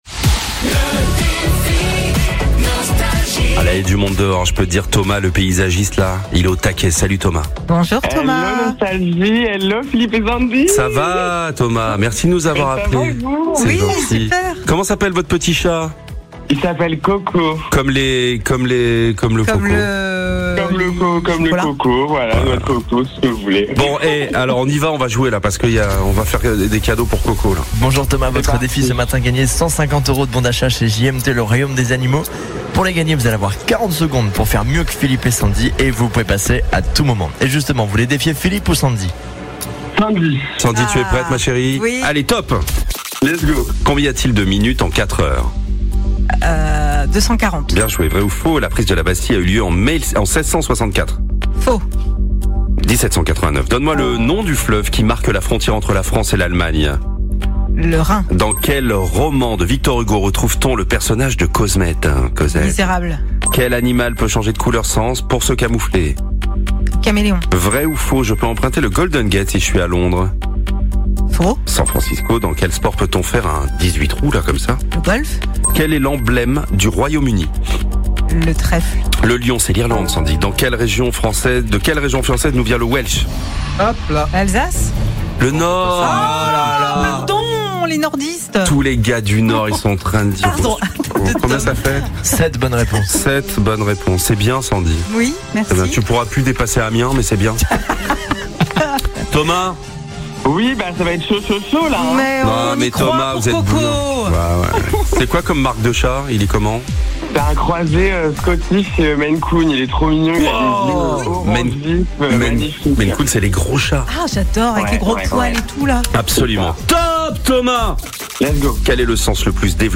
Au programme : Bonne humeur et tous les tubes 80 !